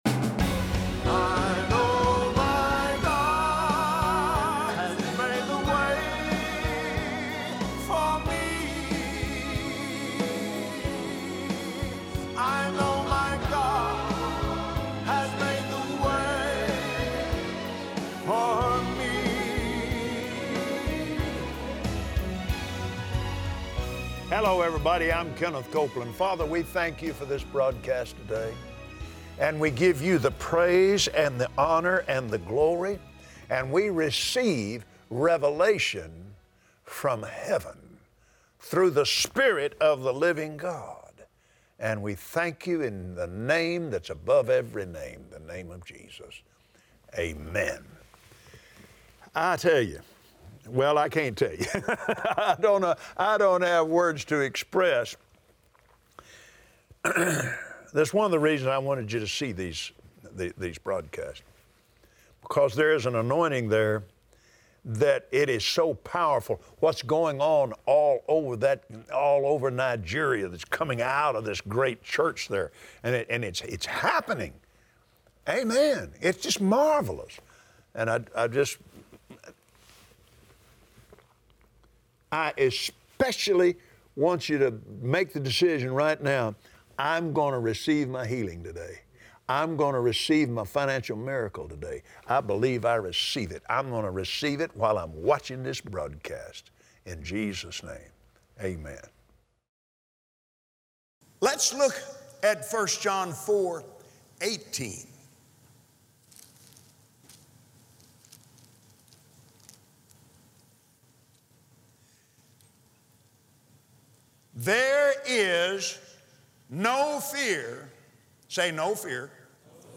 Believers Voice of Victory Audio Broadcast for Friday 09/01/2017 Listen to Kenneth Copeland on Believer’s Voice of Victory as he shares how we have access to the same anointing that Jesus had when He was on the earth. Learn to tap into the anointing that God gave to you!